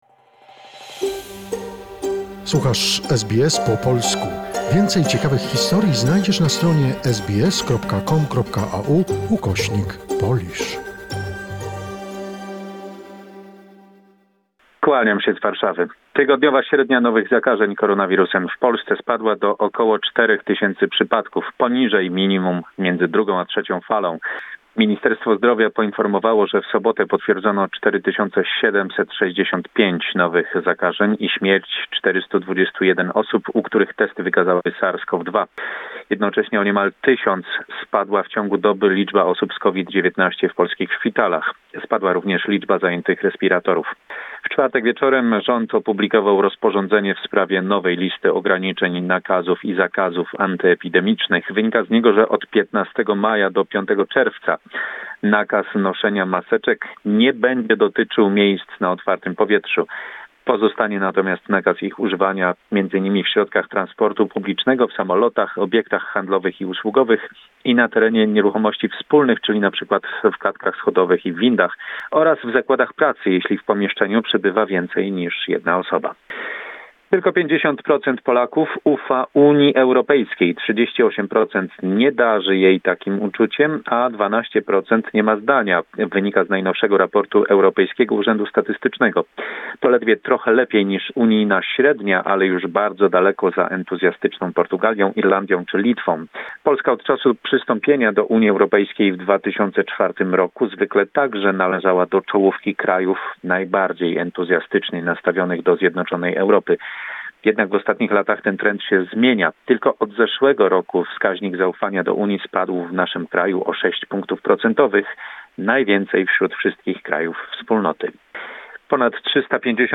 Summary of the most important events in Poland, report